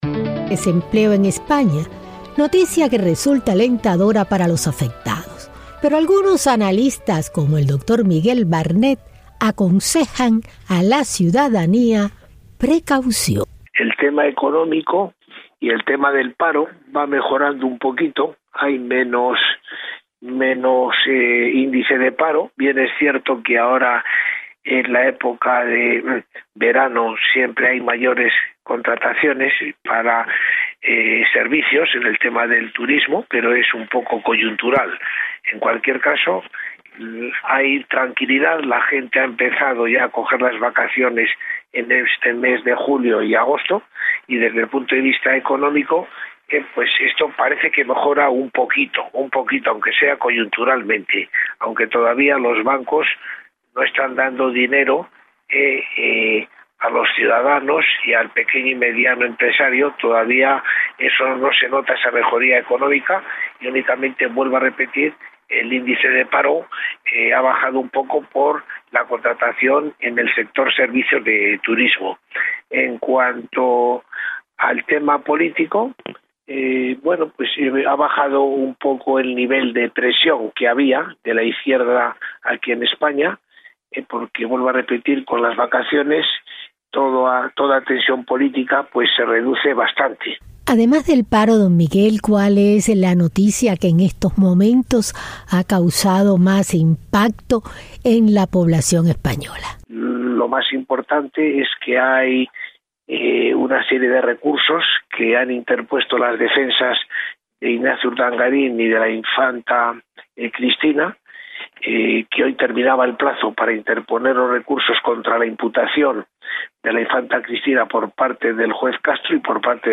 Entrev. en Espana y Venezuela./